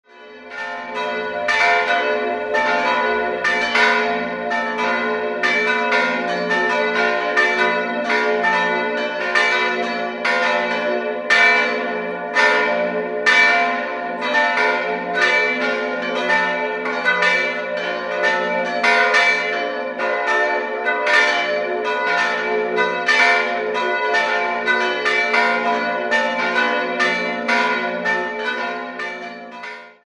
4-stimmiges Geläute: f'-a'-h'-d''
Die Glocken f', h' und d'' wurden 1949 von der Gießerei Hamm in Regensburg gegossen und sind auf die Töne f'+8, h'+2 und d''+2 gestimmt.
bell
Ein Geläute mit stark verzogener Schlagtonlinie: Die Grundglocke ist mehr als einen Halbton zu tief geraten, wodurch das ursprünglich geplante Parsifal-Motiv ziemlich verzerrt wird.